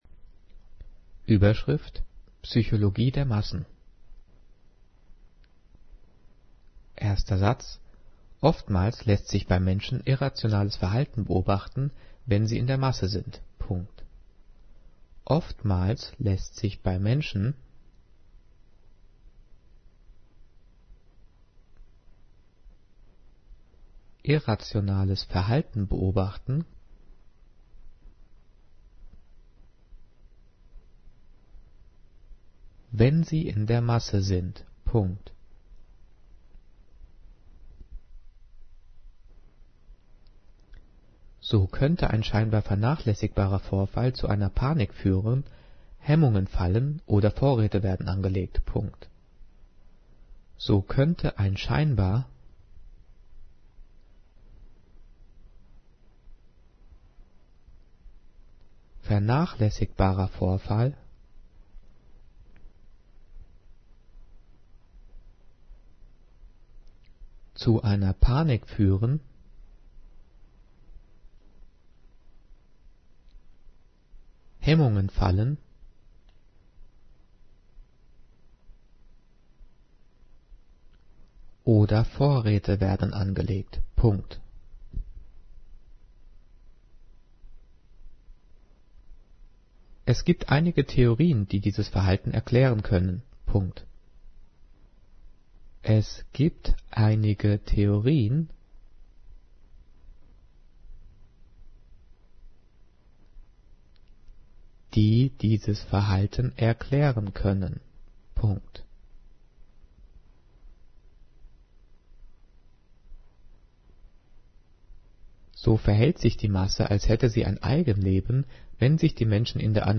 Diktat: "Psychologie der Massen" - 9./10. Klasse - Umlaute
Diktiert: